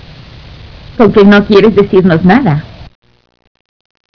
Por motivos de espacion estos sonidos estan hechos en formato wav de 8 bits, por eso es que no tienen mucha calidad, si quieres oir las versiones mas claras, solo Escribeme Y yo te mando los MP3 sin ningun problemas.